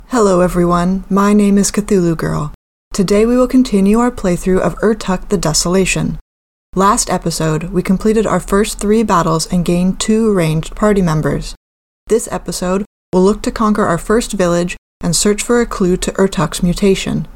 The best way I can describe it is ‘stiff’ and ‘hollow’, which if I understand correctly might mean I’m missing some of the ‘warmer’ voice frequencies. I think maybe the audio also degrades at the louder moments and the noise reduction isn’t tuned correctly (explaining part of why I have to silence things when I’m not speaking).
I’m currently using a Blue Snowball iCE USB mic and recording at my desk. The environment is usually quiet except for the computer hum.